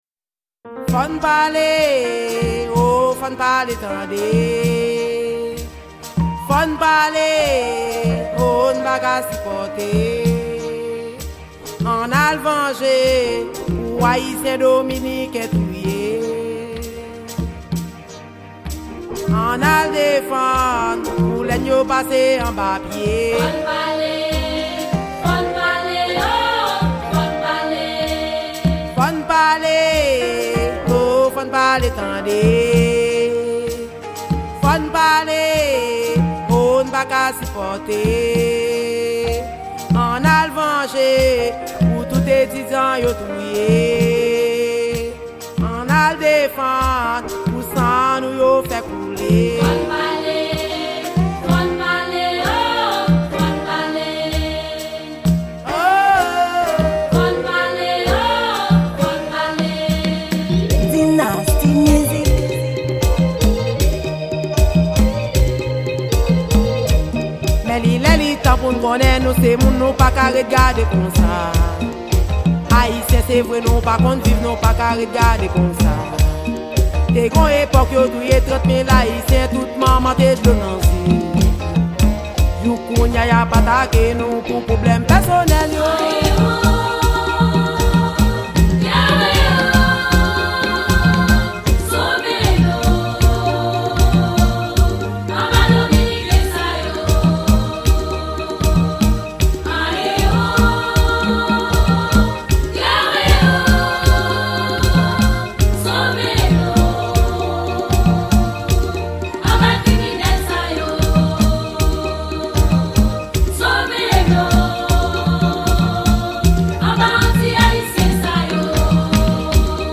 Genre: Racine